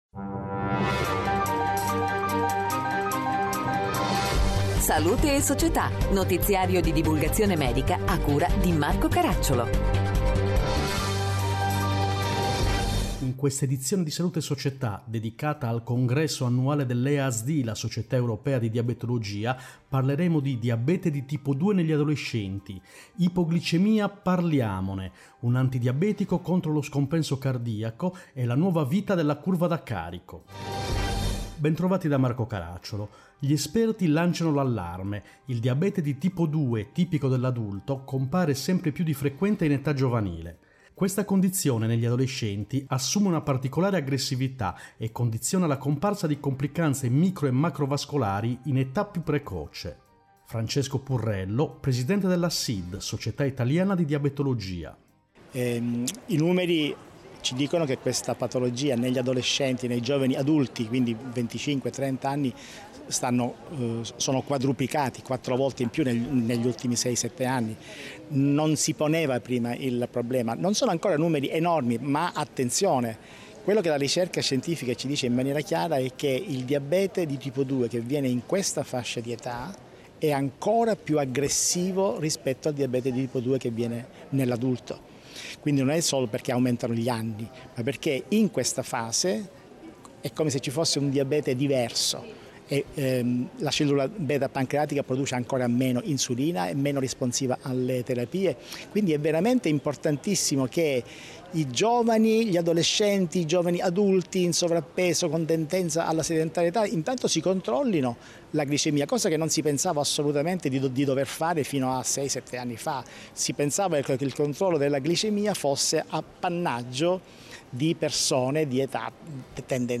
In questa edizione: 1. Easd 2019, Diabete di tipo 2 negli adolescenti 2. Easd 2019, Ipoglicemia parliamone 3. Easd 2019, Un antidiabetico contro lo scompenso cardiaco 4. Easd 2019, Nuova vita per la curva da carico Interviste